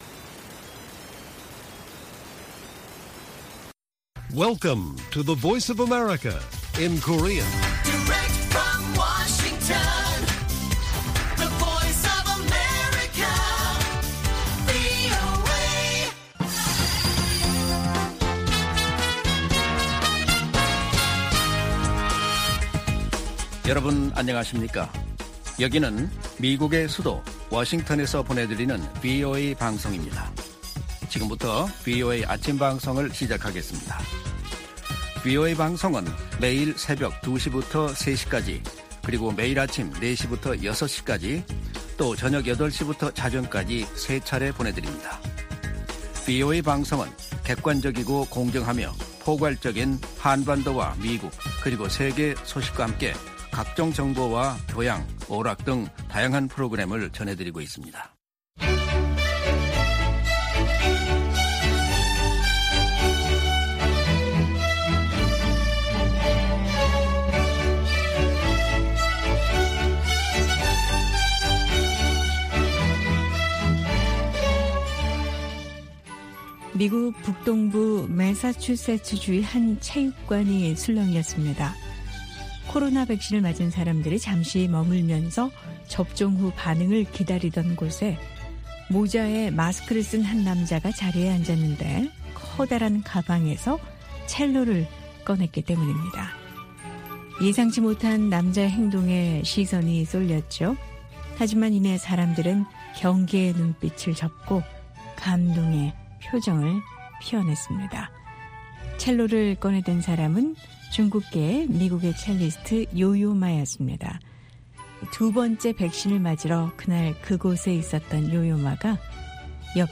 VOA 한국어 방송의 월요일 오전 프로그램 1부입니다. 한반도 시간 오전 4:00 부터 5:00 까지 방송됩니다.